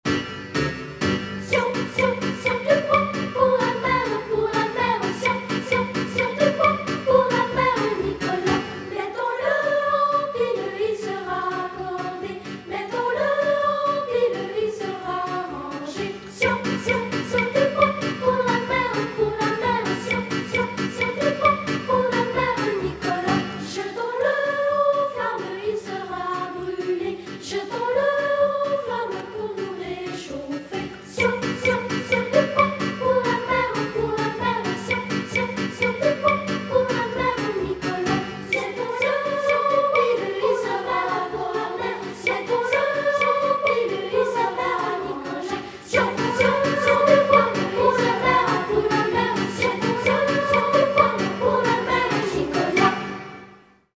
Musique vocale